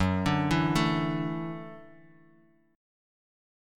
Gbm7#5 chord